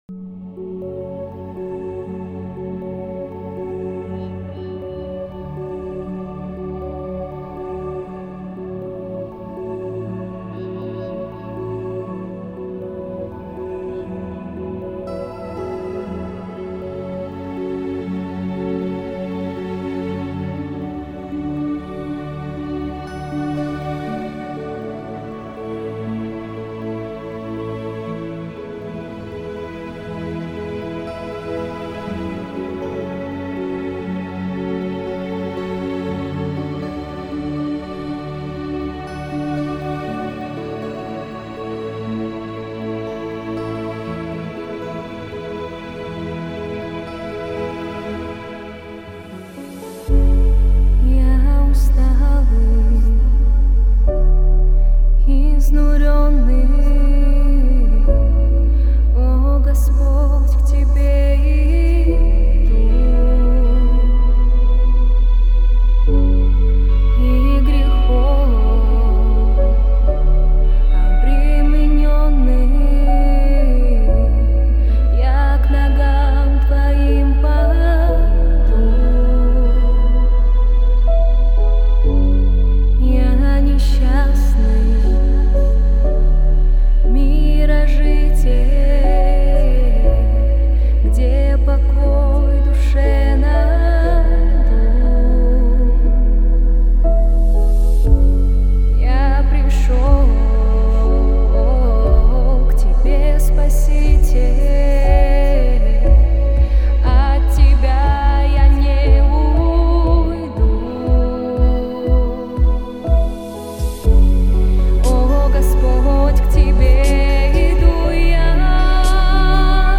217 просмотров 93 прослушивания 13 скачиваний BPM: 120